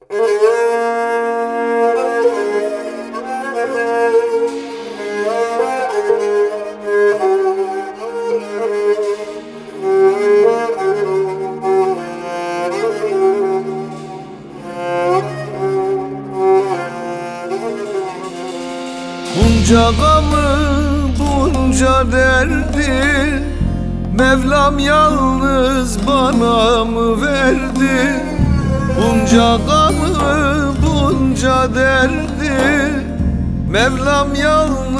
Акустические струны и тёплый вокал
Жанр: Фолк